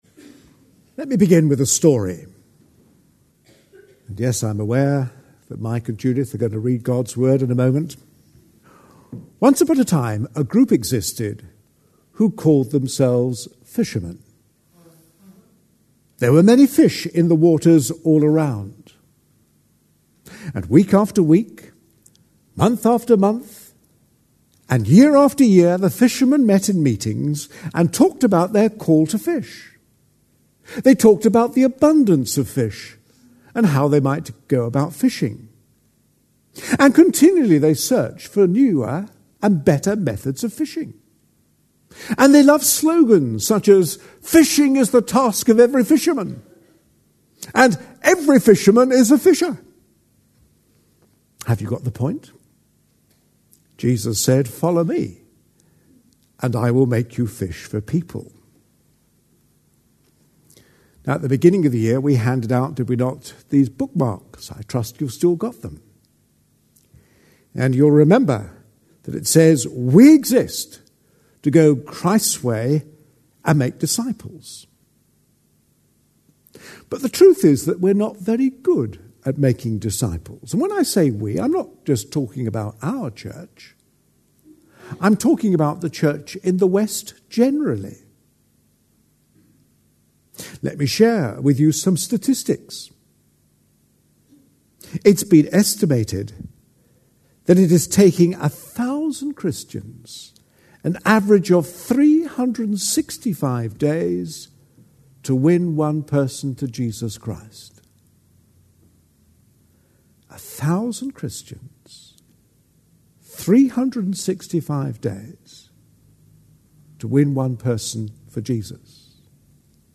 A sermon preached on 30th January, 2011, as part of our A Passion For.... series.